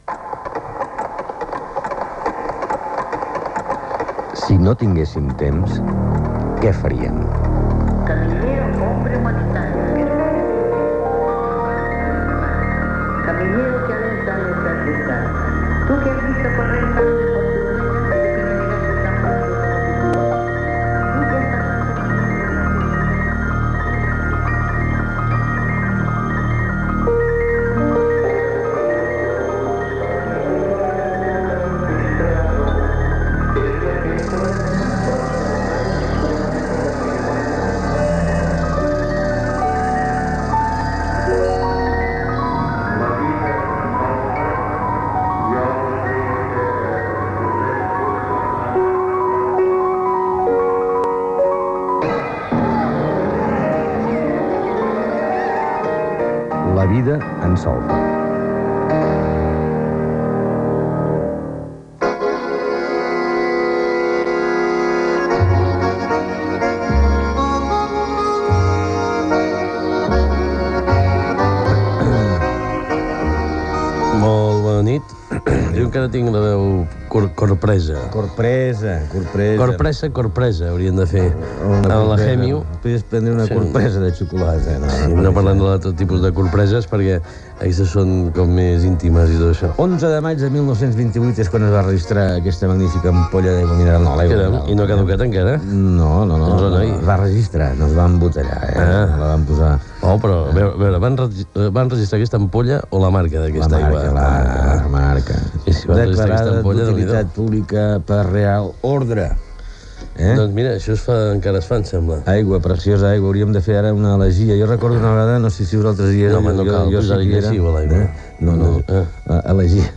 Careta del programa, presentació parlant de la pesseta i dels treballadors a les obres de Barcelona Gènere radiofònic Entreteniment